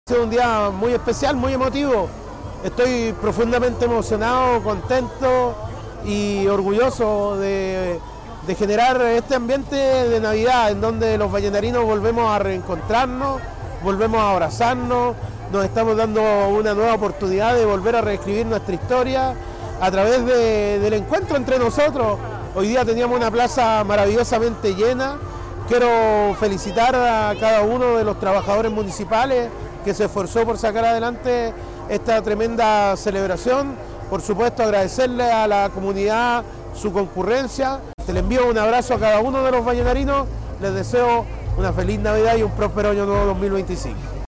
El alcalde Víctor Isla Lutz destacó la importancia del evento como símbolo de unidad y esperanza:
cuna_Alcalde_arbol.mp3